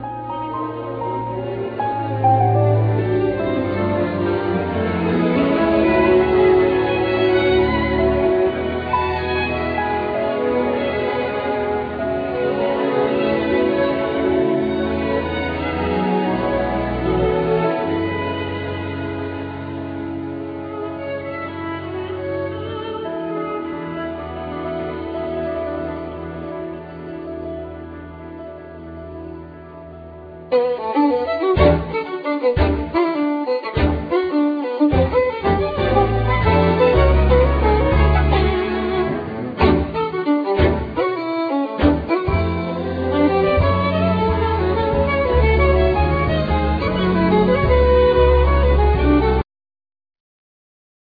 Acoustic Violin,Electric Violin,Tenor Violin
Piano,French horn
Double Bass
Drums
Percussion
Violin,Viola,Cello,Arco Bass